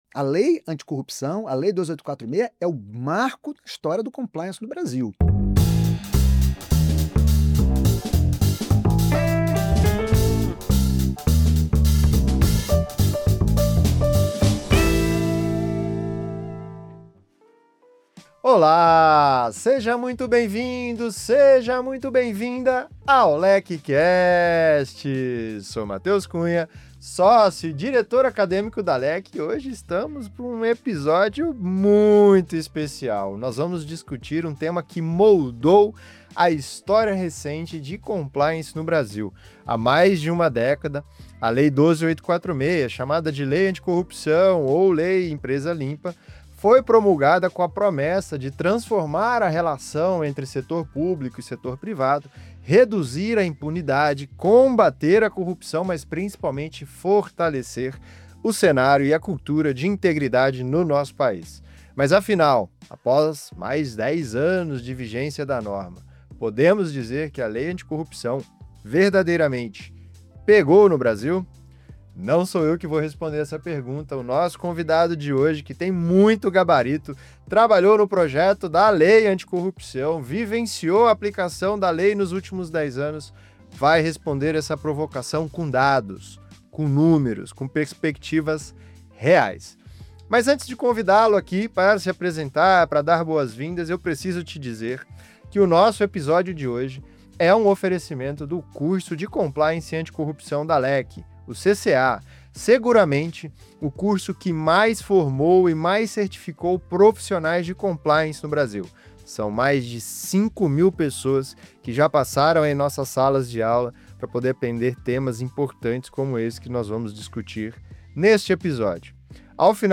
para um bate-papo imperdível sobre os avanços, os desafios e o impacto real da Lei Anticorrupção após mais de uma década.